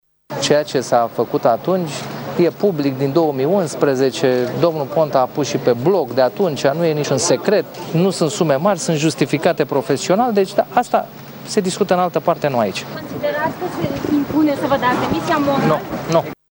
Dan Şova a afirmat, in urma cu putin timp, la ieşirea de la Direcţia Naţională Anticorupţie, că procurorii au extins acuzaţiile.